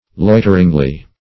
loiteringly - definition of loiteringly - synonyms, pronunciation, spelling from Free Dictionary Search Result for " loiteringly" : The Collaborative International Dictionary of English v.0.48: Loiteringly \Loi"ter*ing*ly\, adv. In a loitering manner.
loiteringly.mp3